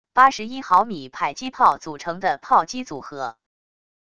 81mm迫击炮组成的炮击组合wav音频